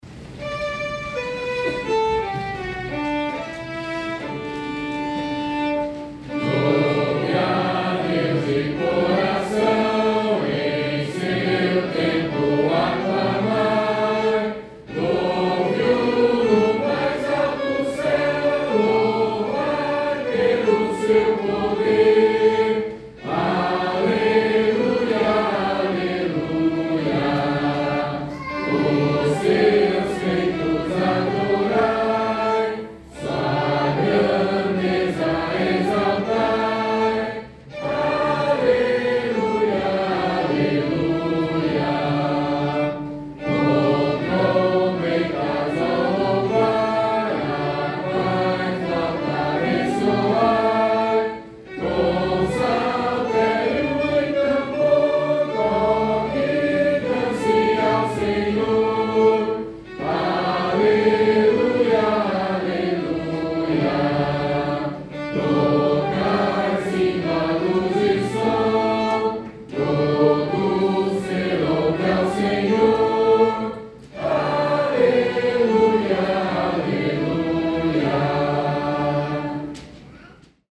Modo: jônio
Harmonização: Claude Goudimel, 1564
salmo_150A_cantado.mp3